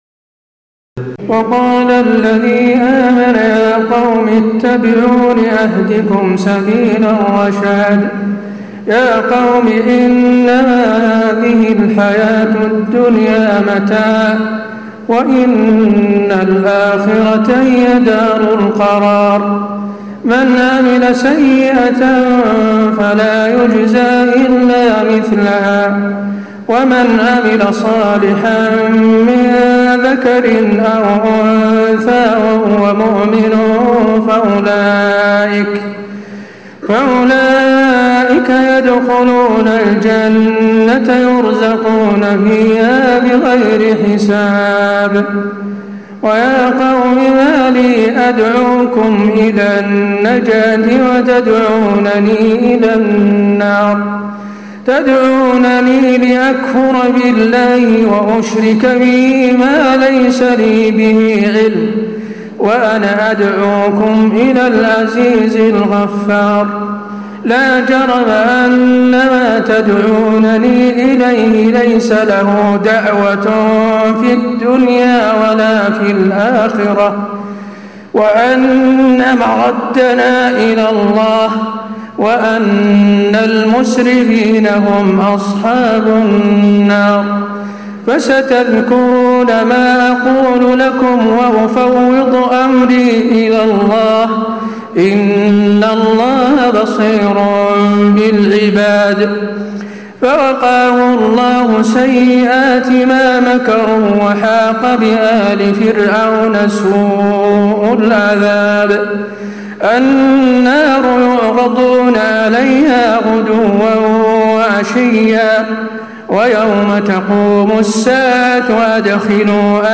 تراويح ليلة 23 رمضان 1436هـ من سور غافر (38-85) وفصلت (1-46) Taraweeh 23 st night Ramadan 1436H from Surah Ghaafir and Fussilat > تراويح الحرم النبوي عام 1436 🕌 > التراويح - تلاوات الحرمين